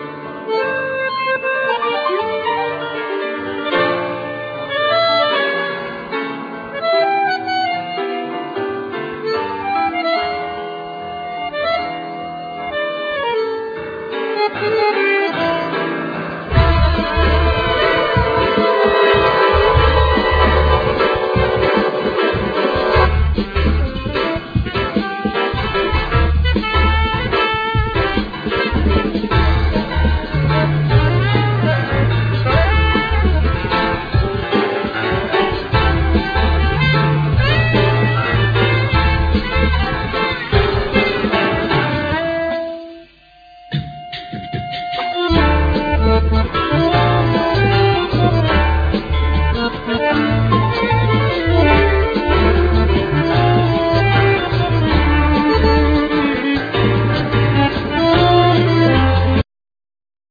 Bandneon,Glockenspiel
Piano,Synthsizer
Violin
Tenor saxophone
Drams,Tabla